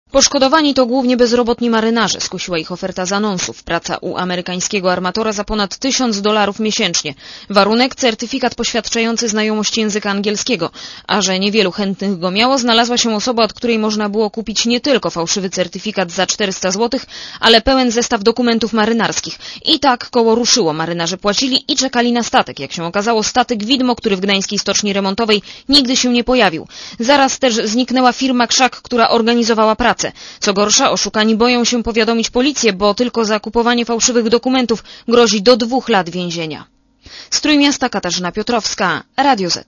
Posłuchaj relacji reporterki Radia Zet